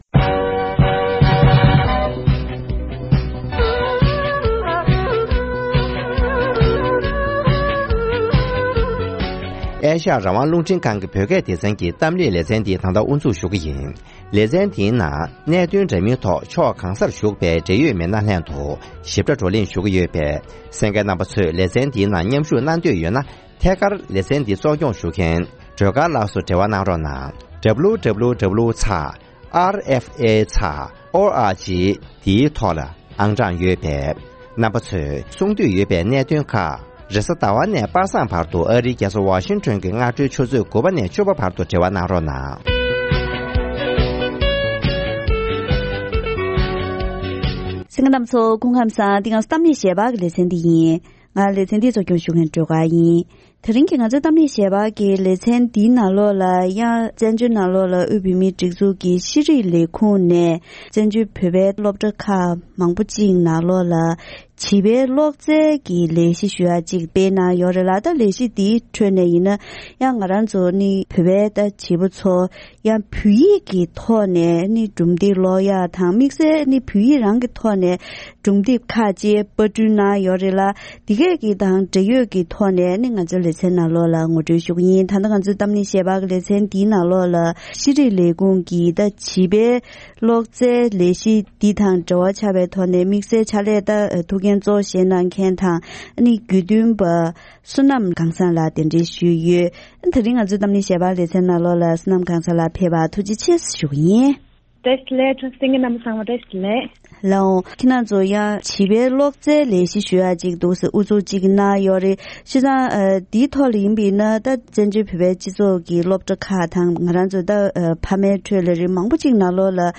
གཏམ་གླེང་ཞལ་པར
འབྲེལ་ཡོད་མི་སྣ་ཁག་ཅིག་དང་ལྷན་དུ་བཀའ་མོལ་ཞུས་པར